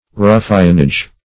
Search Result for " ruffianage" : The Collaborative International Dictionary of English v.0.48: Ruffianage \Ruf"fian*age\, n. Ruffians, collectively; a body of ruffians.